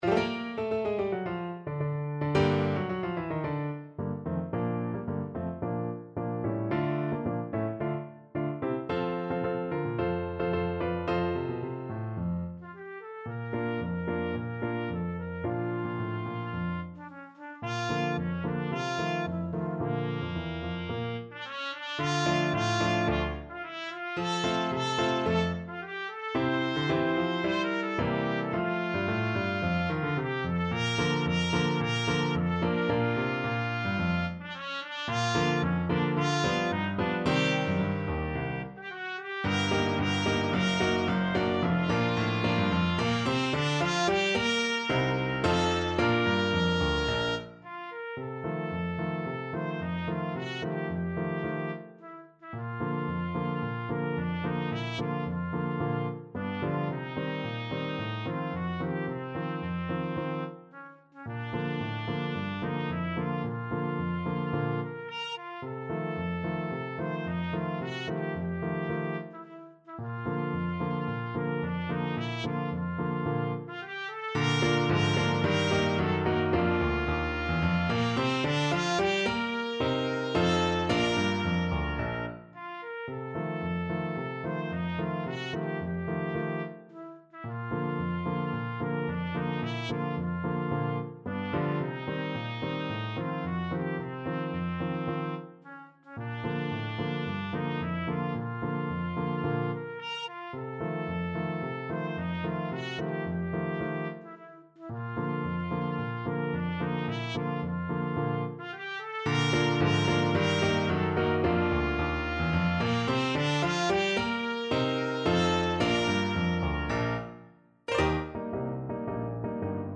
2/2 (View more 2/2 Music)
Classical (View more Classical Trumpet Music)